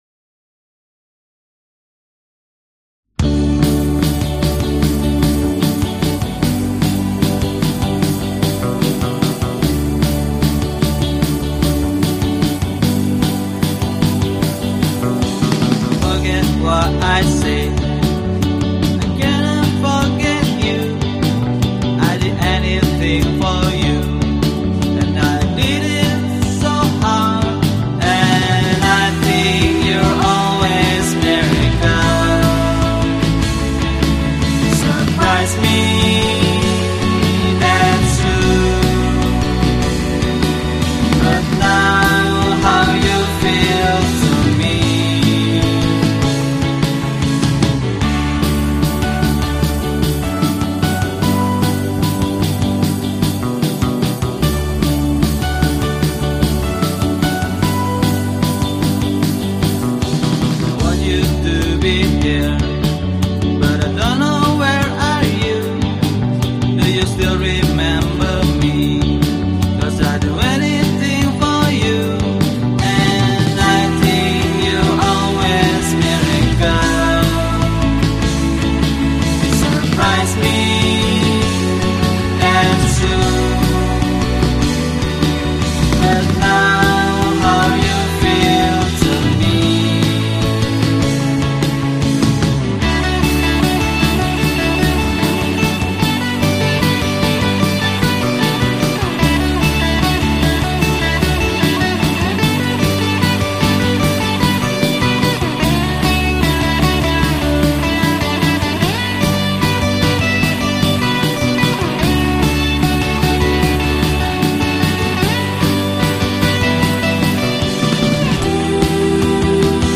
Pop • Jakarta